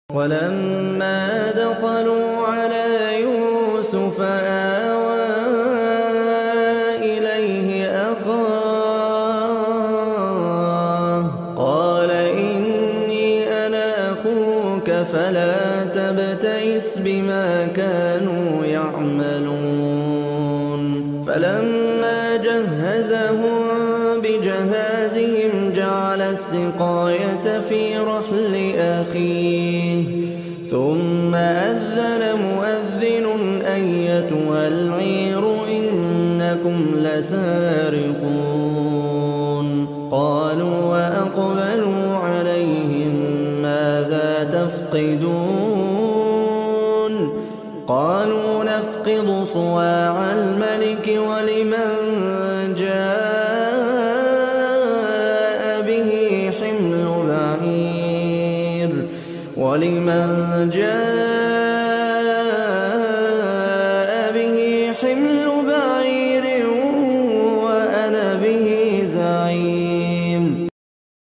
صوت عدب
تلاوة_القرآن